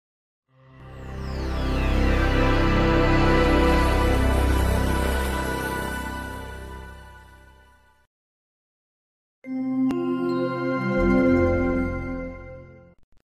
Buenos días Microsoft Windows 98 Startup Sound